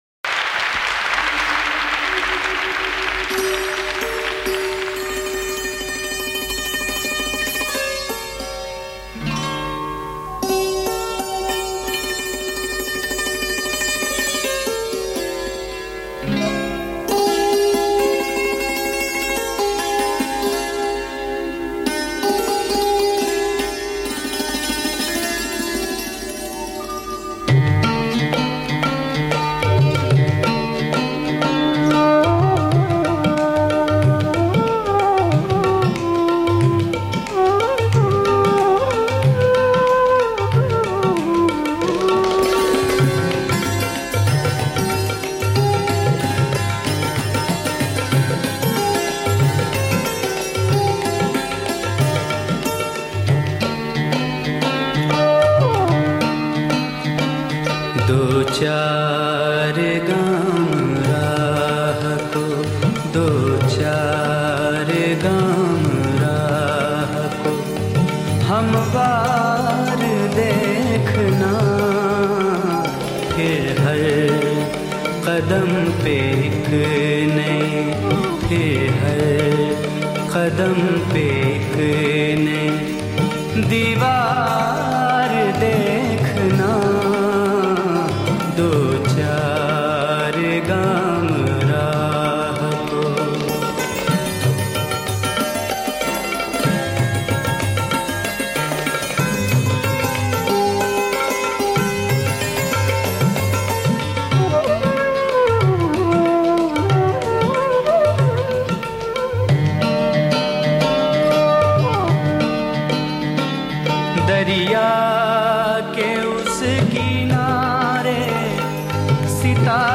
Ghazals
Live